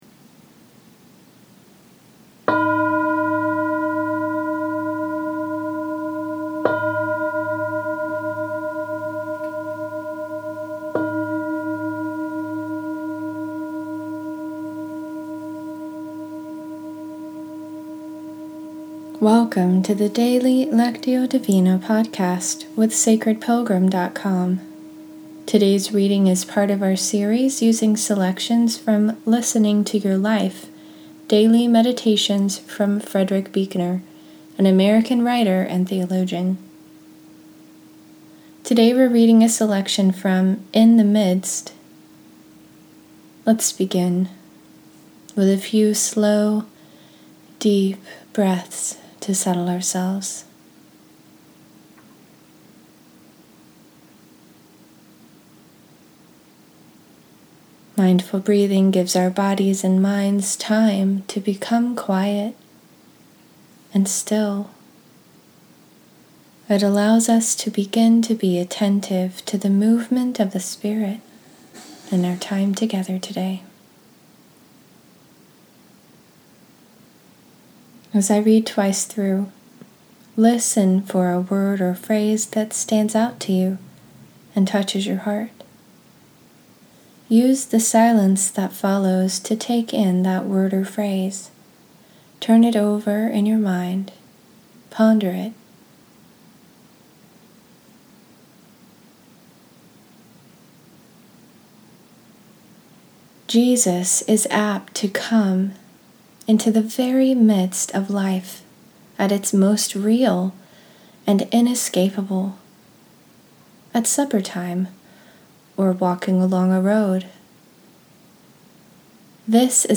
In this episode, we’re continuing our series using selections from Listening to Your Life: Daily Meditations with Frederick Buechner, an American writer and theologian. Today we’re reading a selection from “In the Midst.”